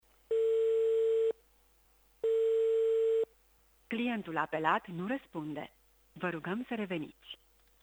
Presedintele interimar al PSD Iasi, Maricel Popa a fost sunat pentru oferi infiormatii referitoare la particviparea Iasului la mitingul de la Bucuresti.